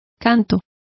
Complete with pronunciation of the translation of songs.